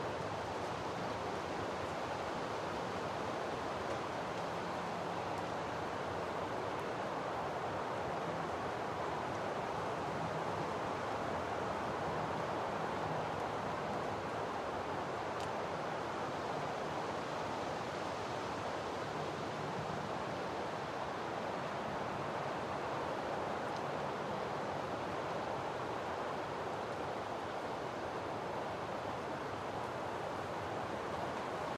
wind.ogg.mp3